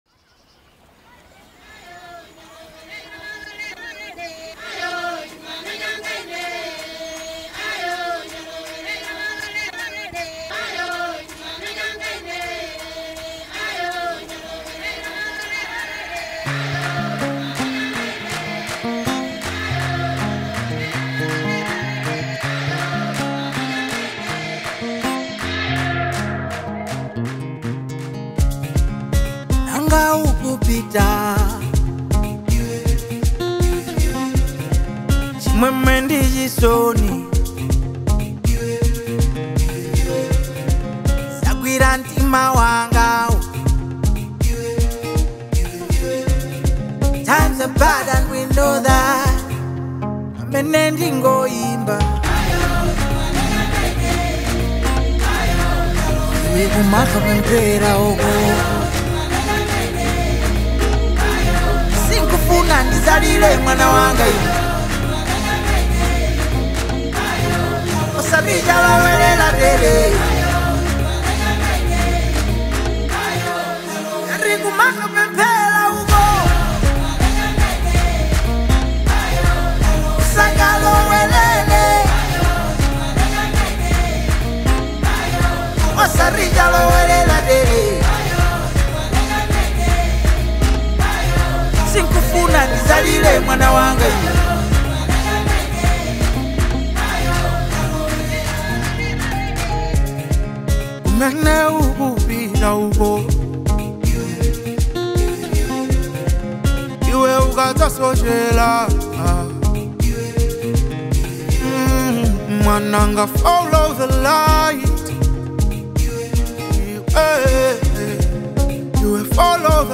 is a tender and emotional love ballad